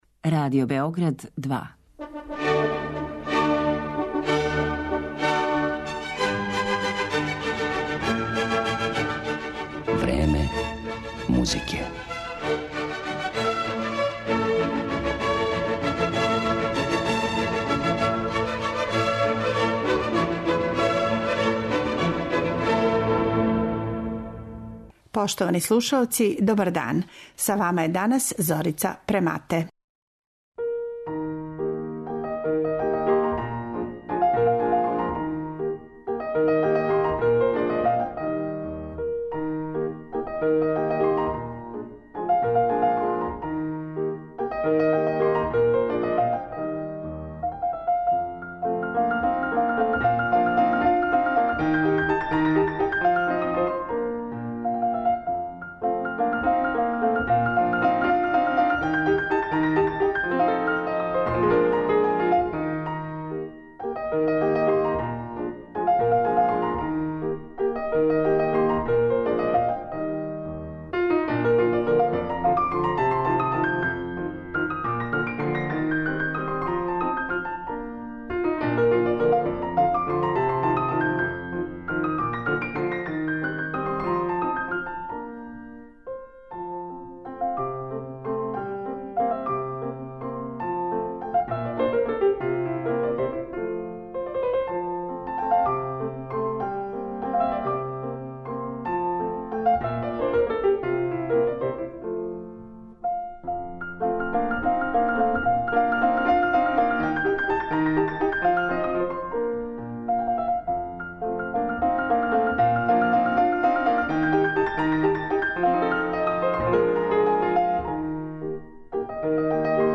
Биће то клавирска, камерна и хорска остварења Марије Шимановске, Фани Менделсон, Кларе Шуман и Сесил Шаминад.